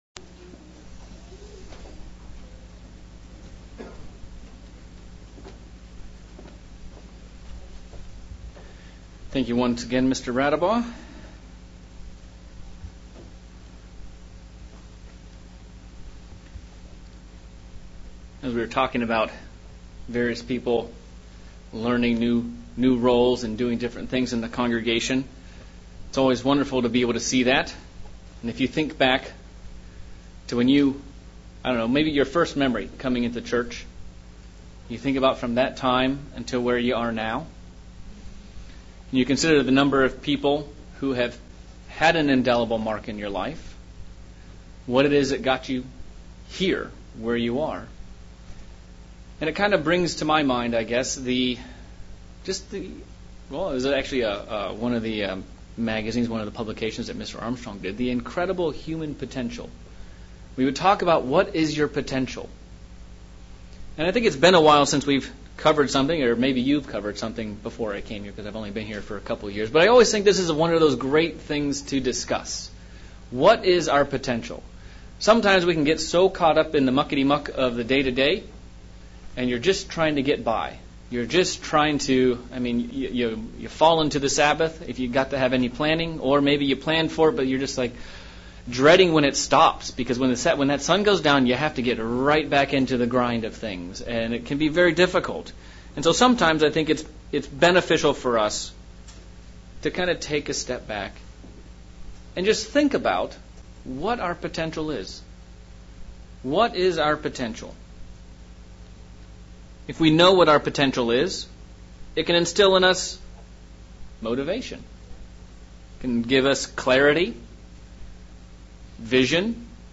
Intro to a 2 part sermon series on what Is Our Purpose. What is our Human Potential? Live a good life, die and go to Heaven as the World believes, or does God have something much more eternal in mind?